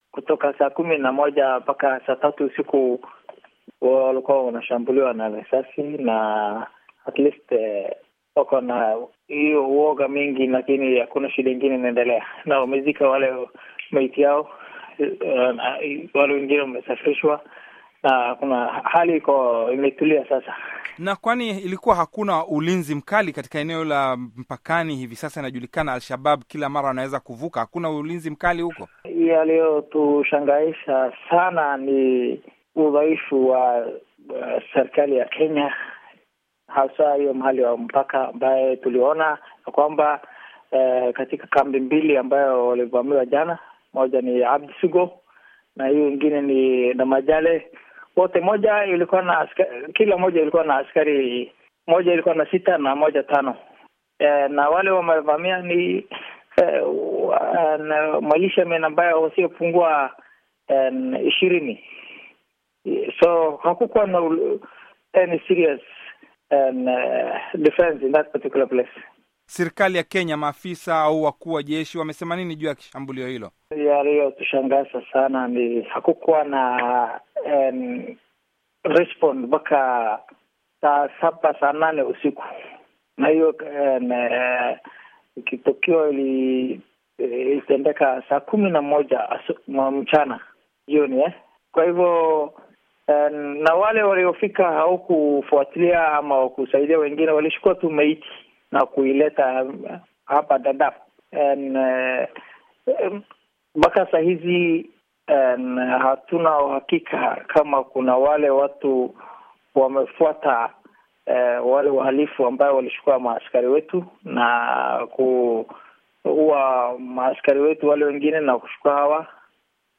Mahojiano na mkazi wa Dadaab - 2:51